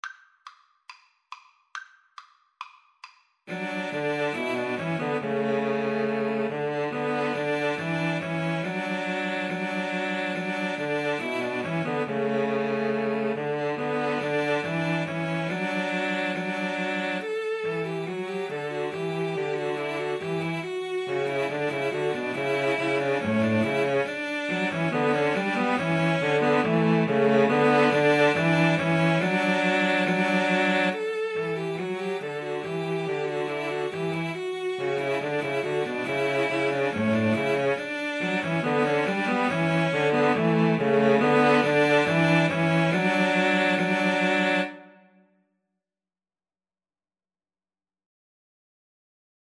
Cello Trio  (View more Easy Cello Trio Music)